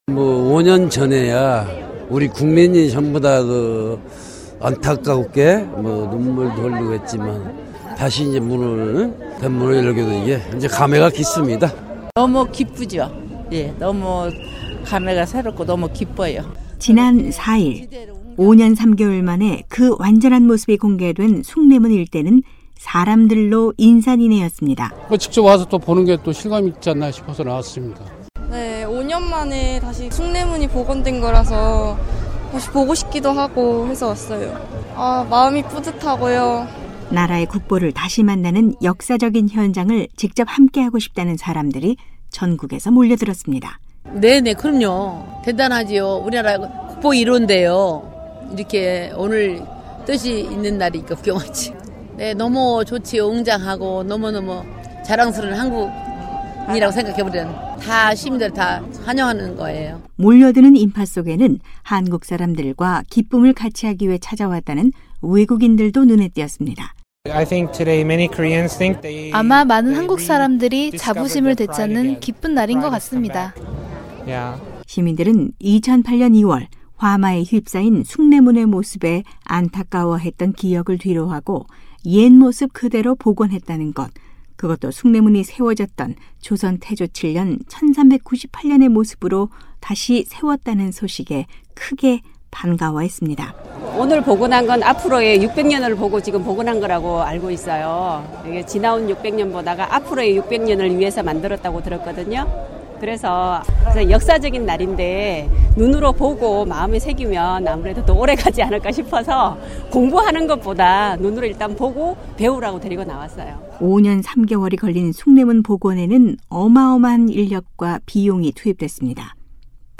소나무와 석재를 기증하고, 성금을 낸 한국민들의 관심과 최고의 전통장인들의 노력으로 옛모습을 되찾았습니다. 지난 4일 거행된 숭례문 복원 기념식 현장